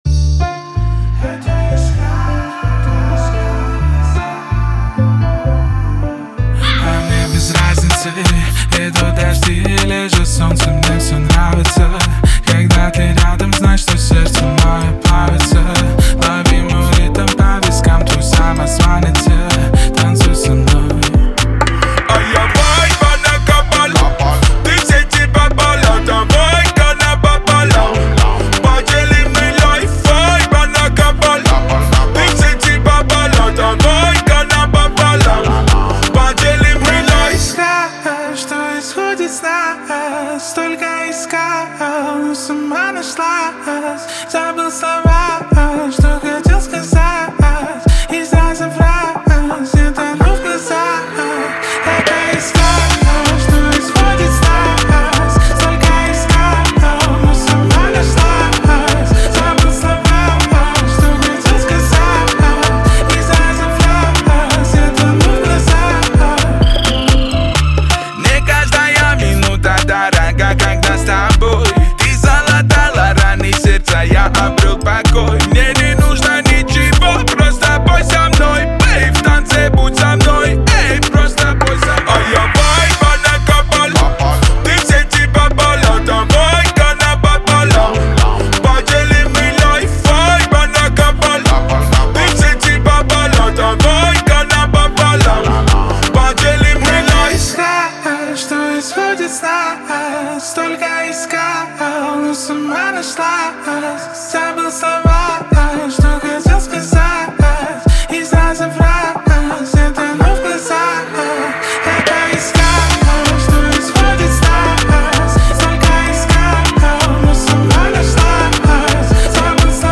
Качество: 320 kbps, stereo
Поп музыка, Рэп